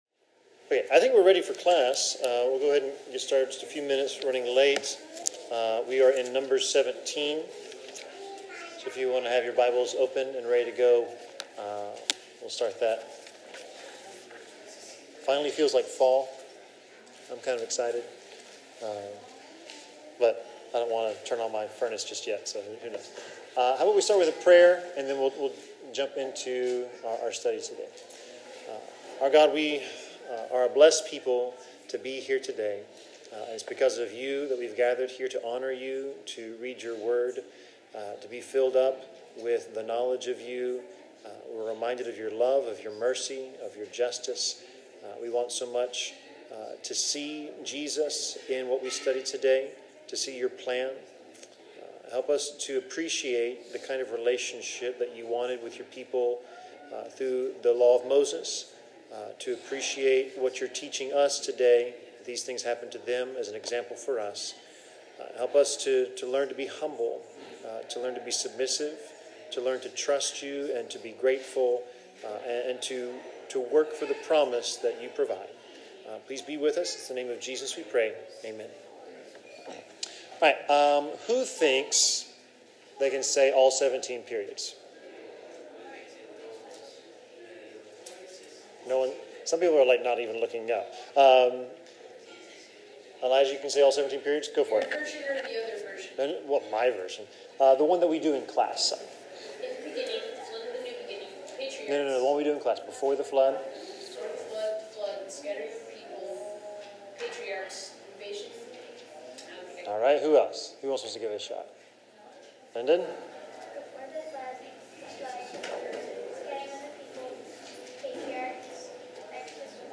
Bible class: Numbers 17-20
Service Type: Bible Class